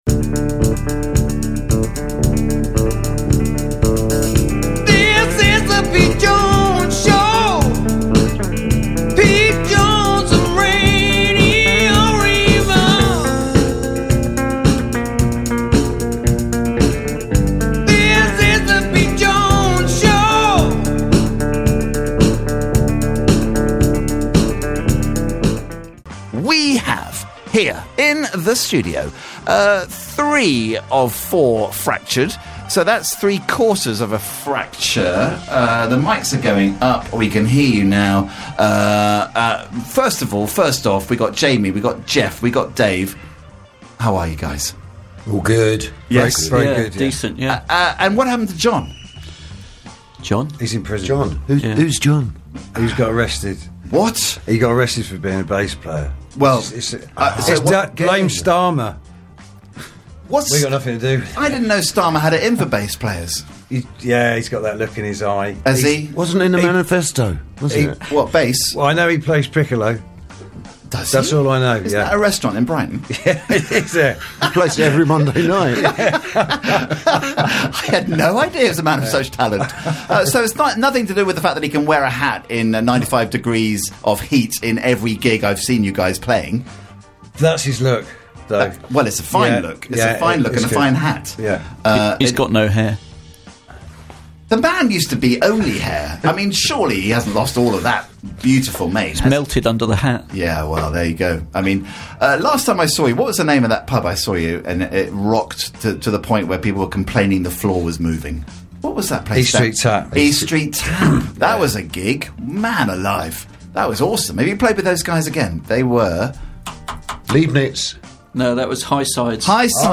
Live chat with Fractured 16th December 2024
Always great to welcome the boys of Fractured back to the Green Room and Reverb studios. It's been a great year for them with fab plans ahead for 2025. 4 tracks played include: